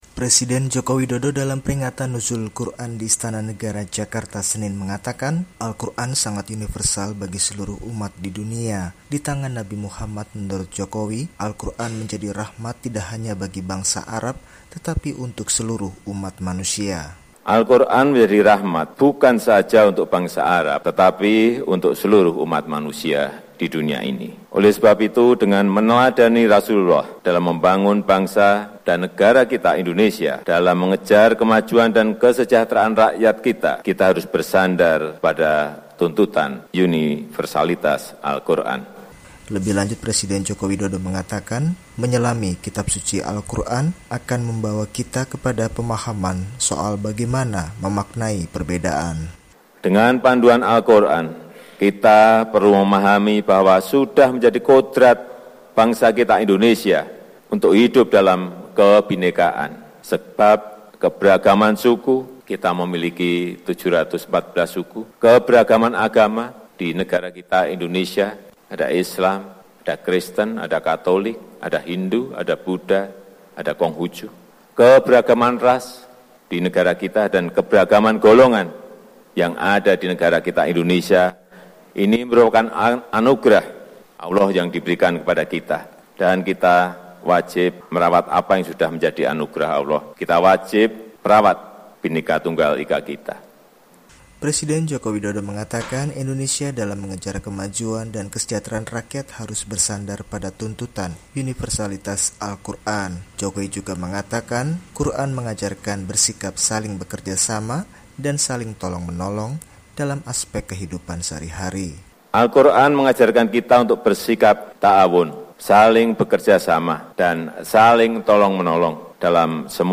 Presiden Joko Widodo dalam Peringatan Nuzulul Quran di Istana Negara Jakarta Senin (12/6) mengatakan, Alquran sangat universal bagi seluruh umat di dunia.